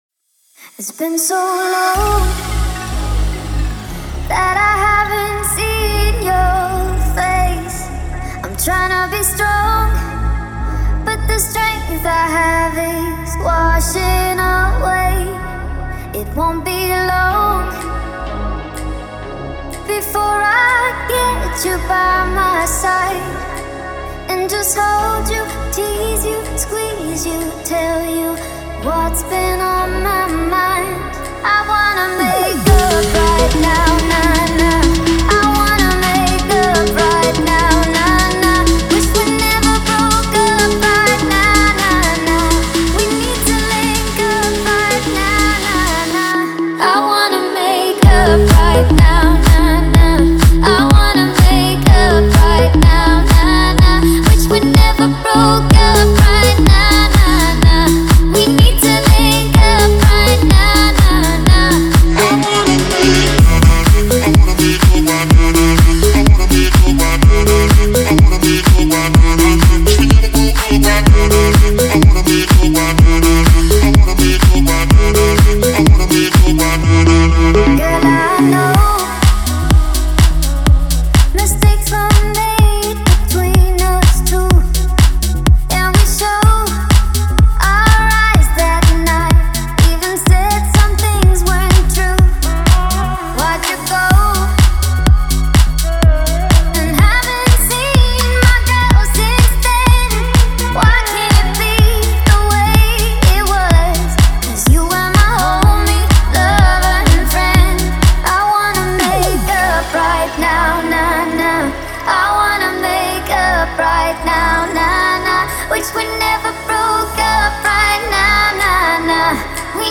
это энергичная поп-песня с элементами электронной музыки